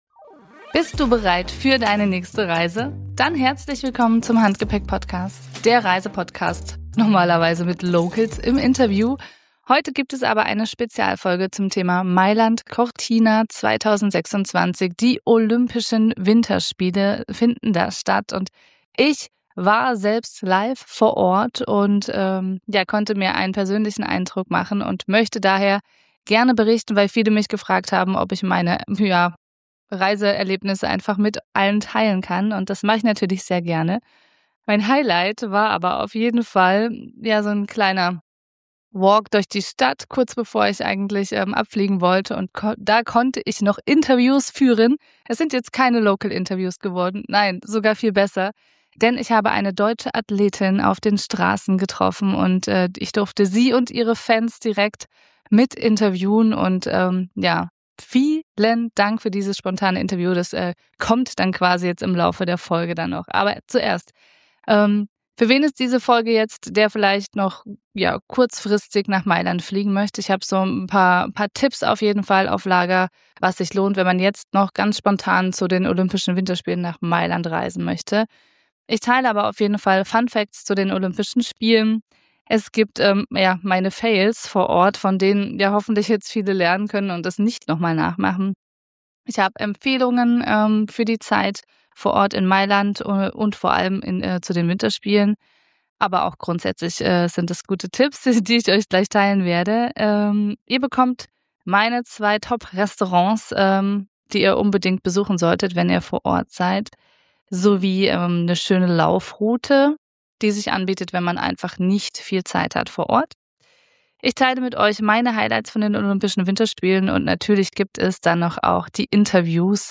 mitten in der Fußgängerzone ein spontanes Interview